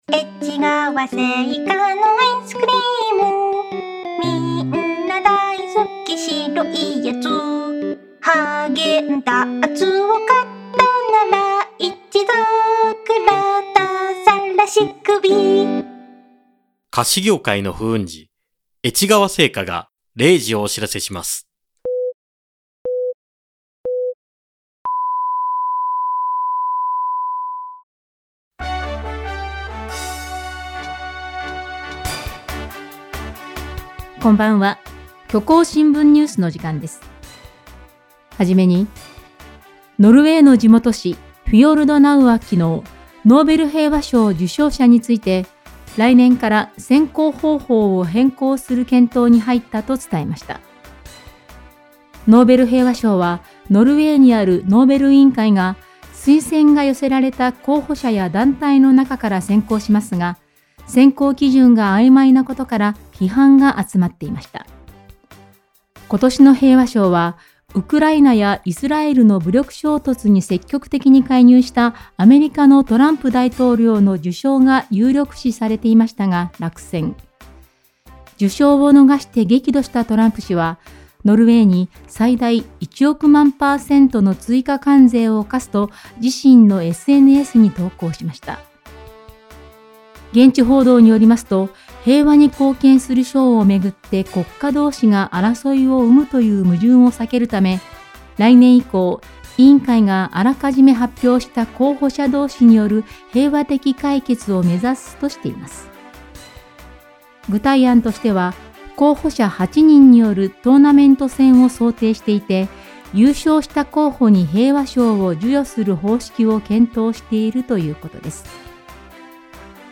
この番組は、他の新聞メディアにはない独自の取材網を持っている虚構新聞社がお届けする音声ニュース番組「虚構新聞ニュース」です。